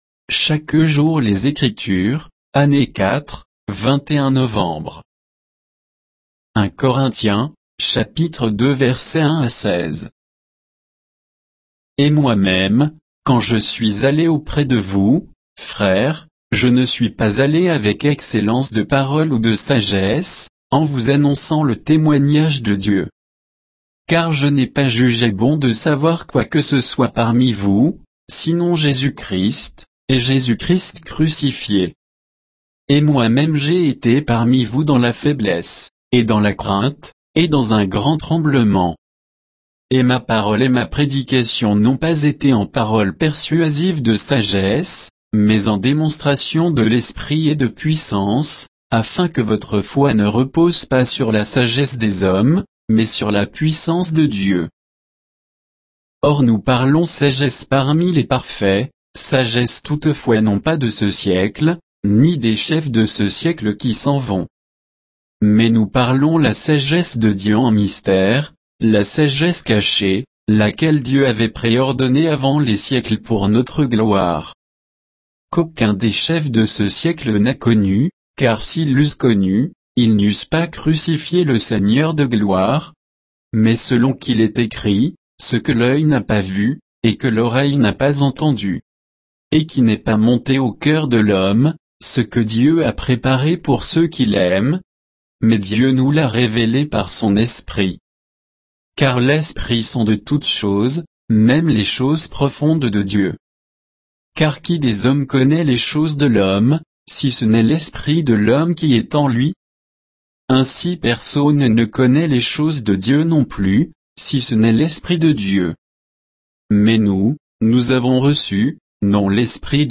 Méditation quoditienne de Chaque jour les Écritures sur 1 Corinthiens 2